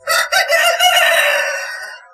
目覚めの音 鶏の鳴き声
コケコッコーの鳴き声は朝おきるときに最適です。
(玉川森林王国というところで録音しました。）
niwatori.mp3